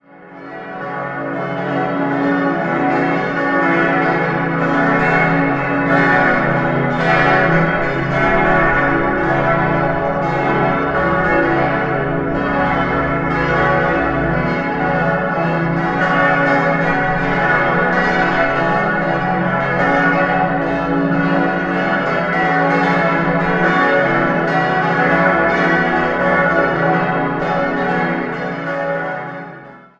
Jahrhundert. 6-stimmiges Geläut: cis'-e'-fis'-gis'-h'-cis'' A lle Glocken wurden 1972 von den Rudolf Perner in Passau gegossen.